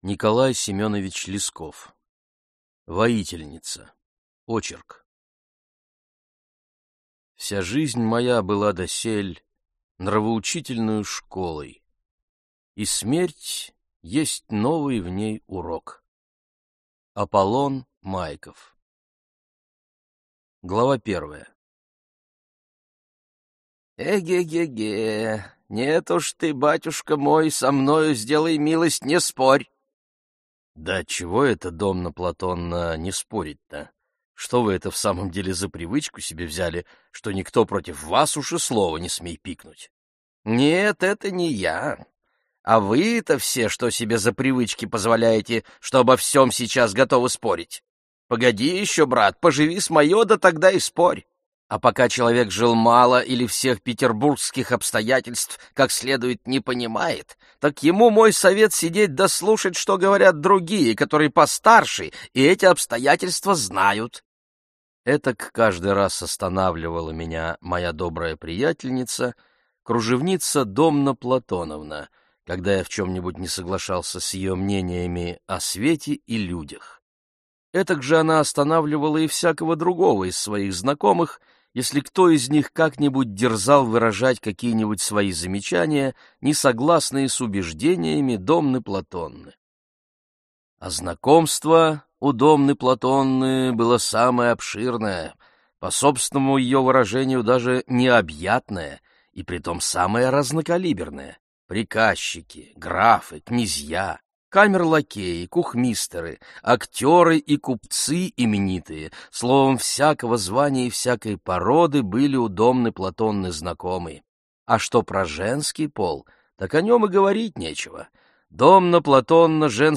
Аудиокнига Воительница | Библиотека аудиокниг